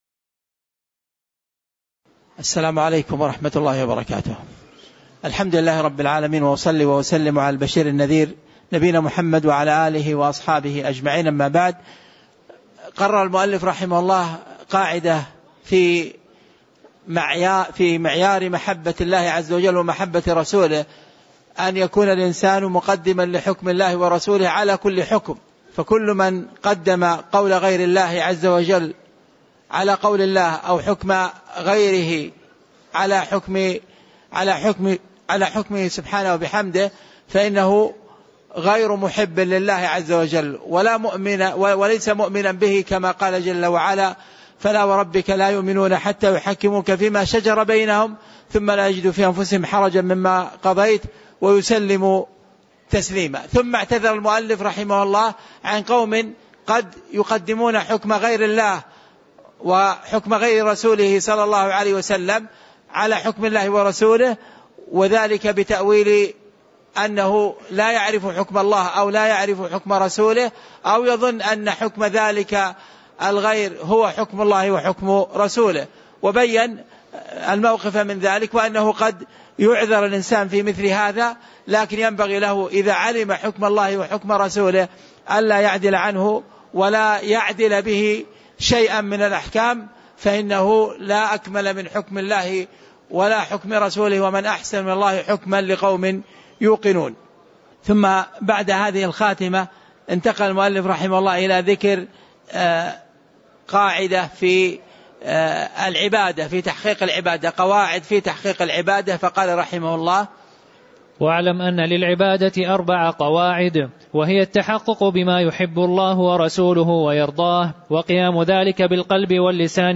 تاريخ النشر ٢٥ ربيع الثاني ١٤٣٩ هـ المكان: المسجد النبوي الشيخ